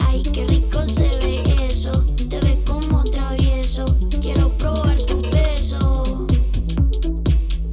▼RIMEオン(ステレオ)のサウンド
上記音源は参考用であり、環境によって聞こえ方が異なりますが、RIMEによる音の変化は感じていただけたかと思います。